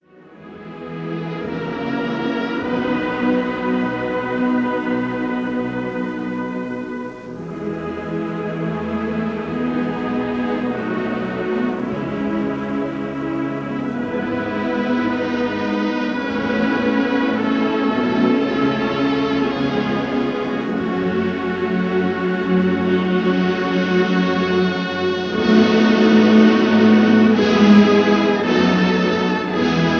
Recorded live in the Crystal Palace, London